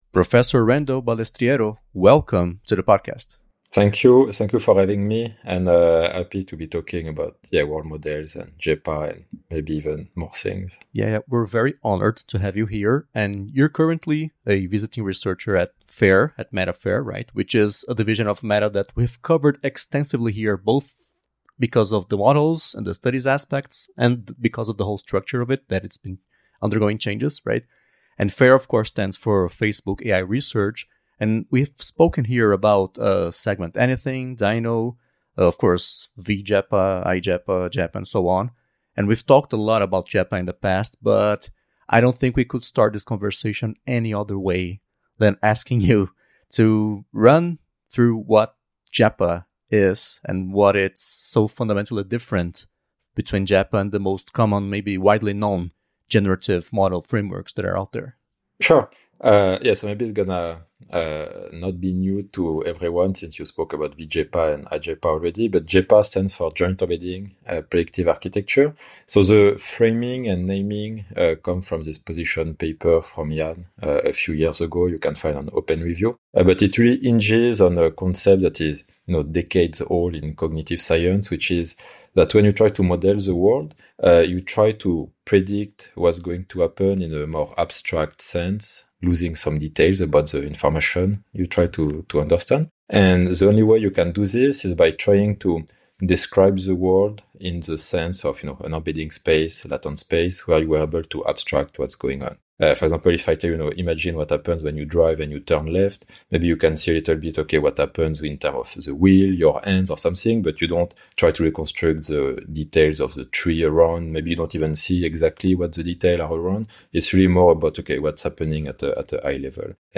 Edição e sonorização: Rede Gigahertz de Podcasts